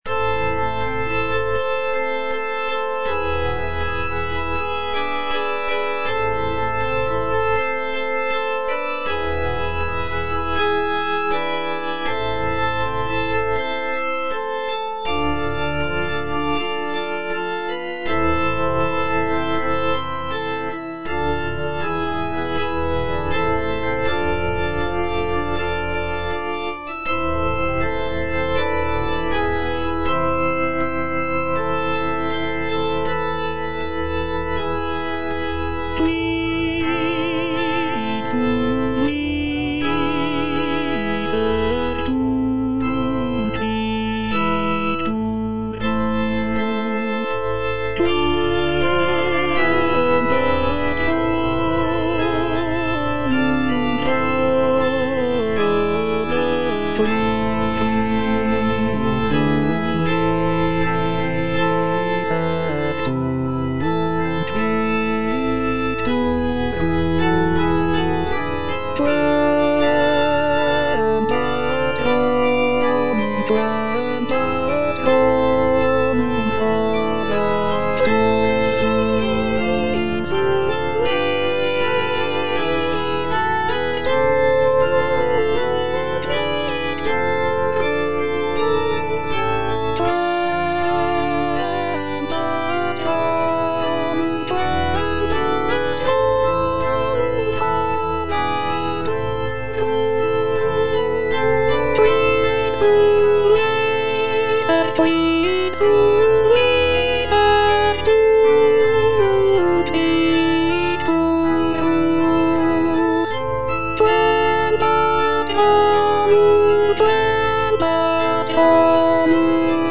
Alto (Voice with metronome
is a sacred choral work rooted in his Christian faith.